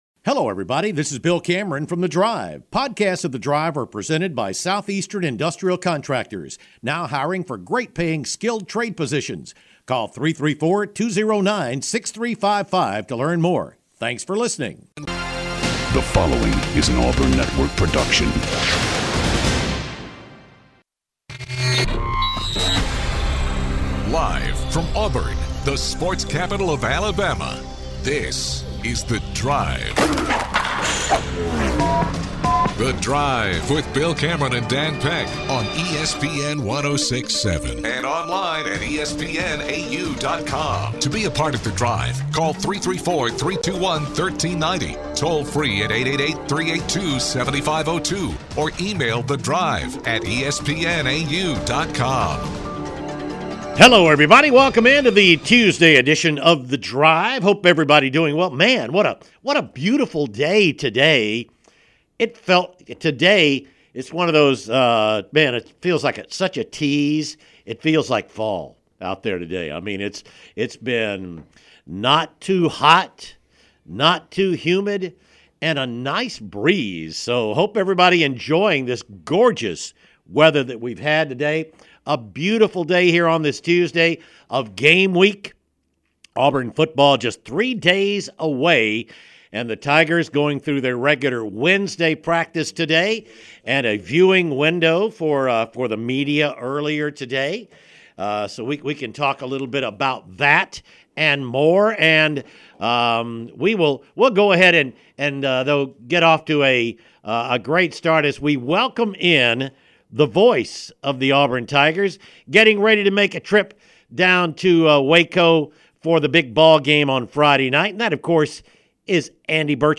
Callers ask about Baylor's potential weaknesses.